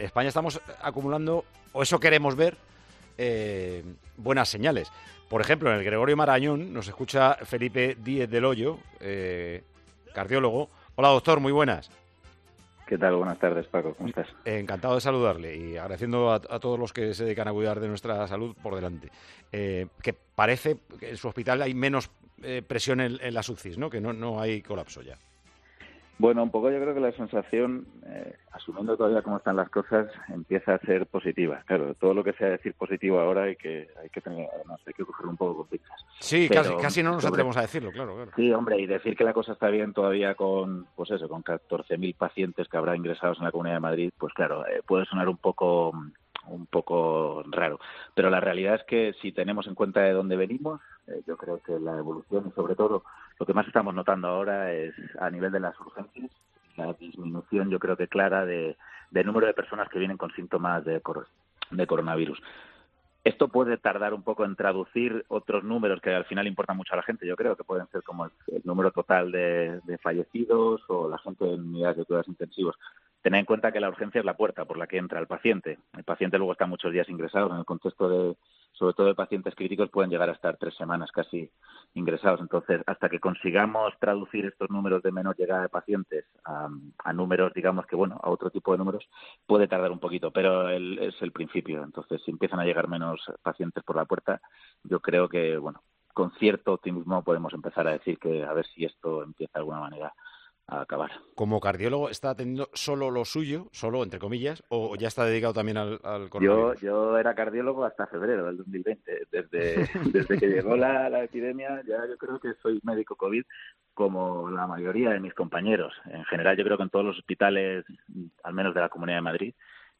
Tiempo de Juego charló este domingo